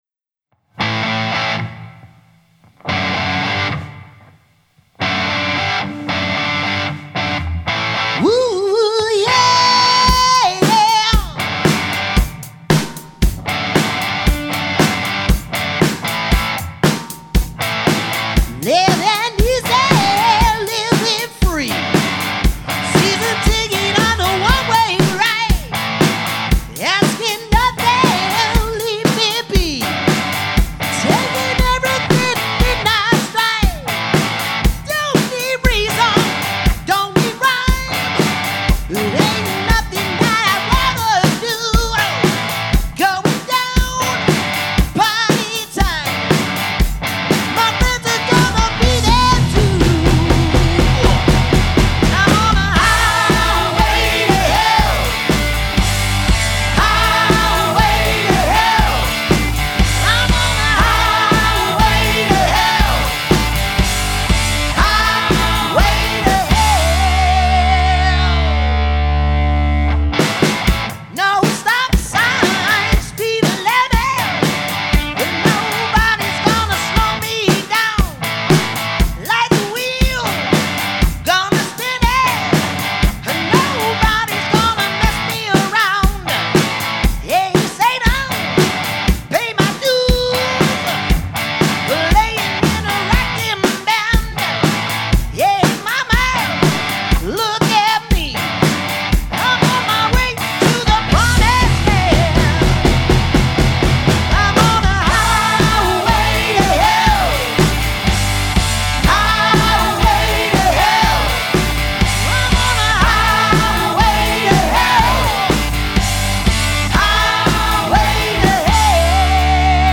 are a classic rock/metal covers band based in Bristol.